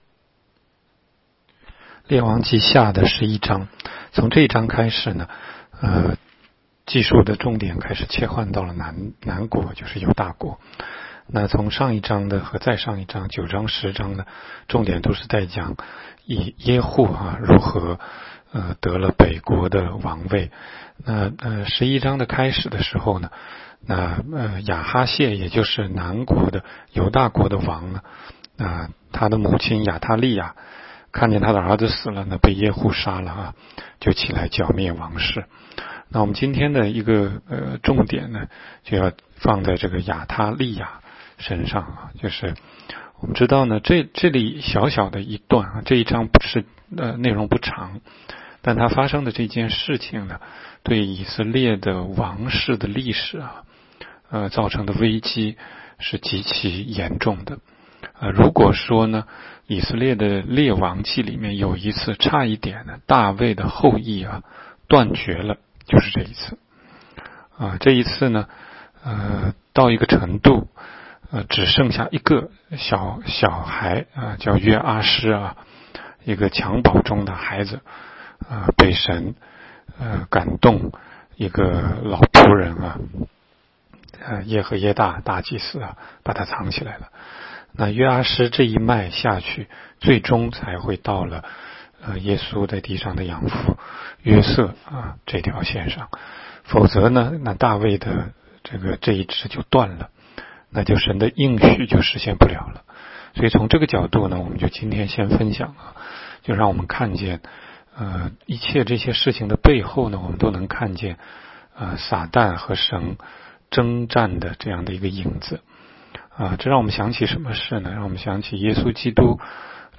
16街讲道录音 - 每日读经-《列王纪下》11章